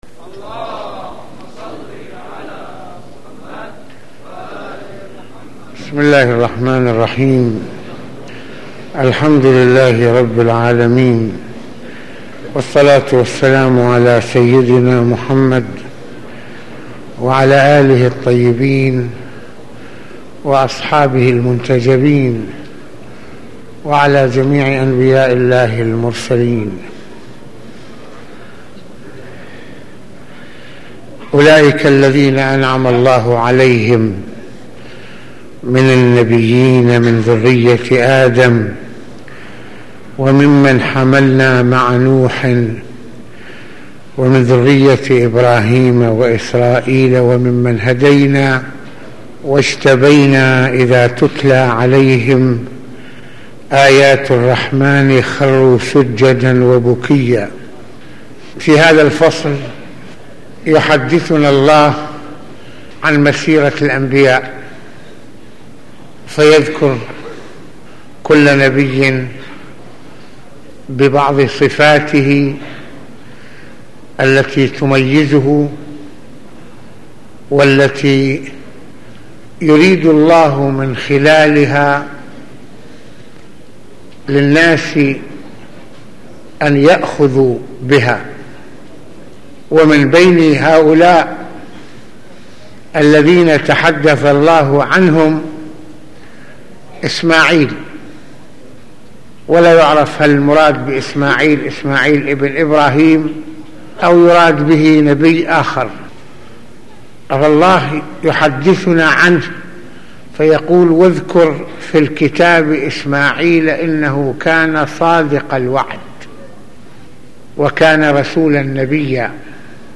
- المناسبة : موعظة ليلة الجمعة المكان : مسجد الإمامين الحسنين (ع) المدة : 25د | 16ث المواضيع : مع النبي اسماعيل في القران الكريم - كيف يكون مجتمعنا مجتمع الصدق والايمان ؟ - ان نكون المثل الاعلى لاطفالنا - اهمية ان تامر اهلك بالصلاة.